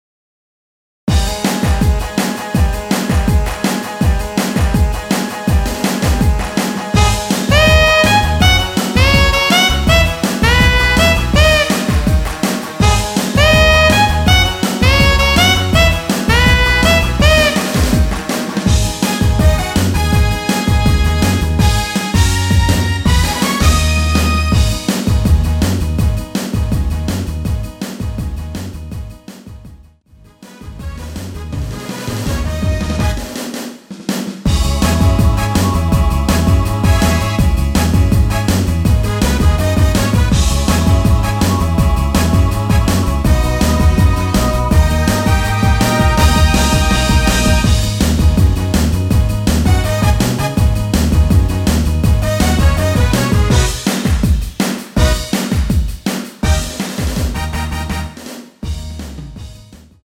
원키에서(+3)올린 MR입니다.
G#m
앞부분30초, 뒷부분30초씩 편집해서 올려 드리고 있습니다.